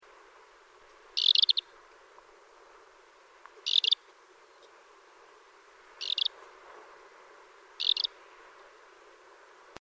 Pikkukiuru / Lesser Short-toed Lark / Calandrella rufescens
2. Kyrgyzstan
Calls, recorded 18 July 2005 at "Salt Lake", just south of Issyk-Kul, west of Tamga, Kyrgyzstan, altitude 1600 m. Many of our group commented that these sounded somewhat different than the calls we heard earlier in Southern Kazakstan. In fact, the greatest difference seems to be that the call is the same the whole time, repetitions of similar note.